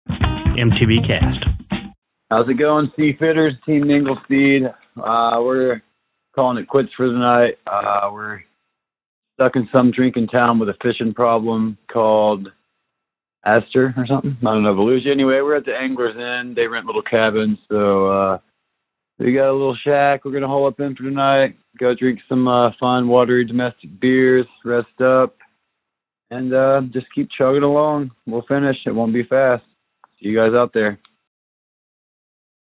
Team Minglespeed called in from Astor!